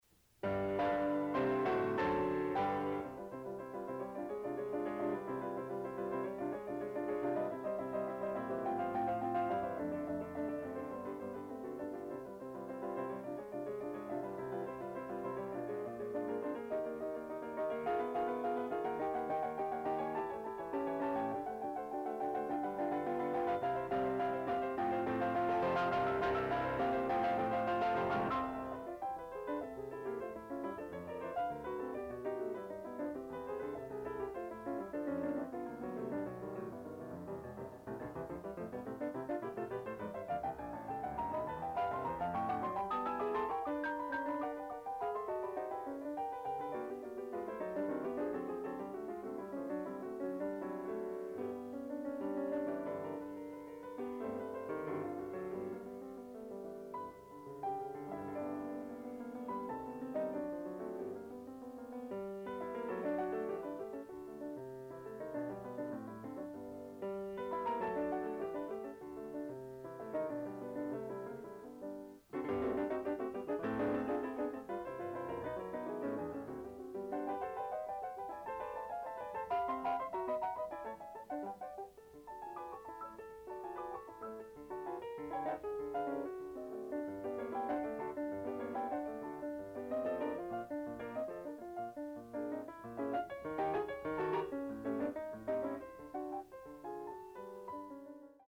Additional Date(s)Recorded September 17, 1973 in the Ed Landreth Hall, Texas Christian University, Fort Worth, Texas
Etudes
Sonatas (Piano)
Waltzes (Music)
Short audio samples from performance